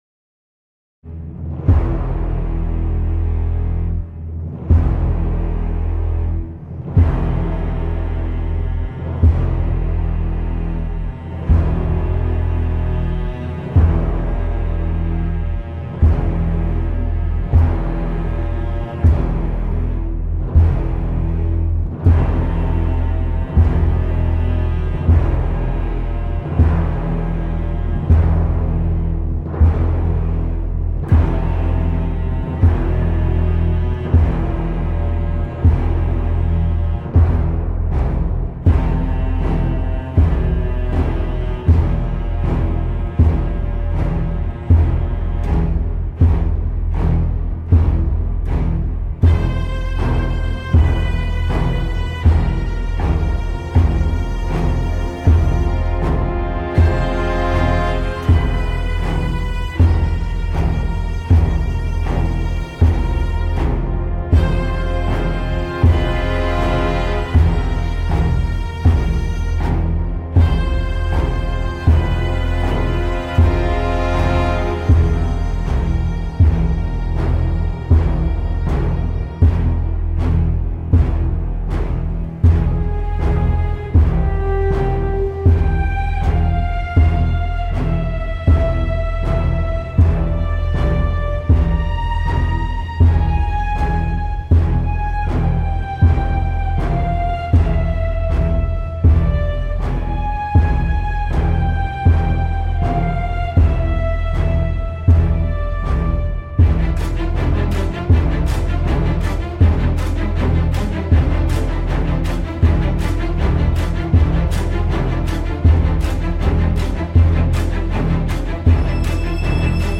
Métronomique, implacable.